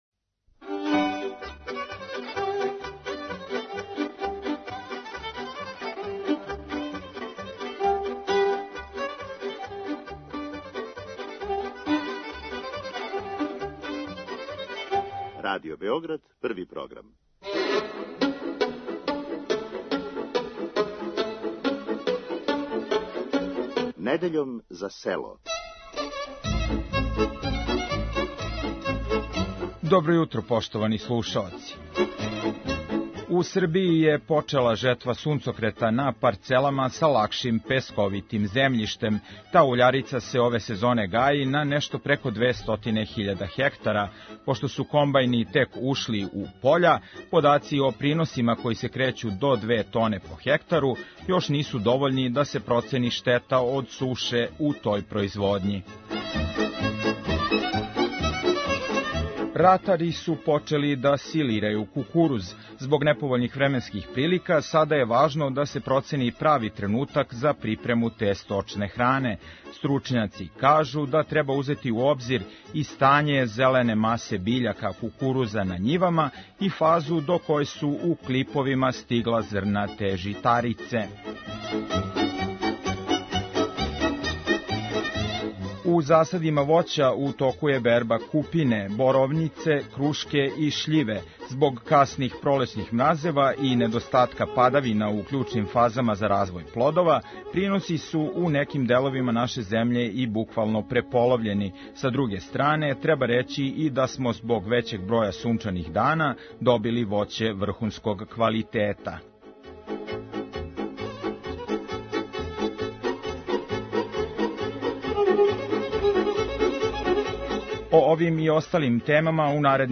Уз остале теме из области пољопривреде у емисији Вас чека и традиционална народна музика из свих делова Србије.